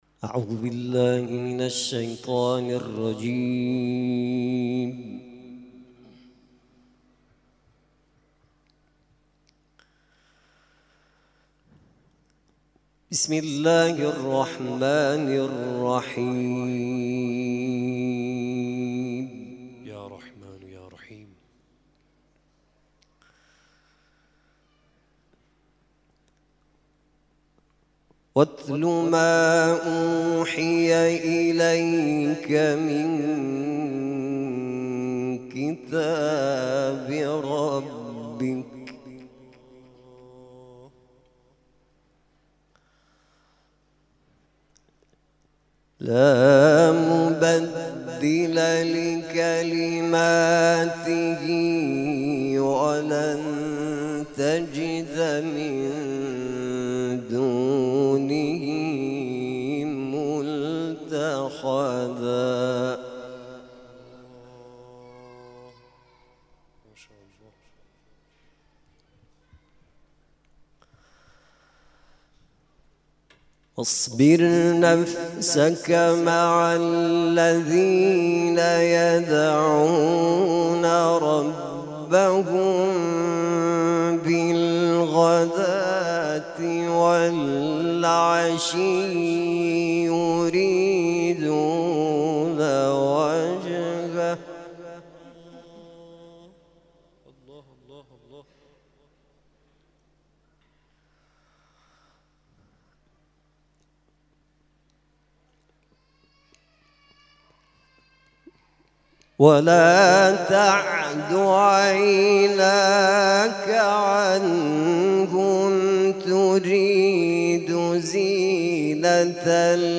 گروه جلسات و محافل: محفل انس با قرآن آستان عبدالعظيم الحسنی(ع) جمعه، ۱۲ آذرماه با حضور دو قاری ممتاز و در جمع زائران این آستان مقدس برگزار شد.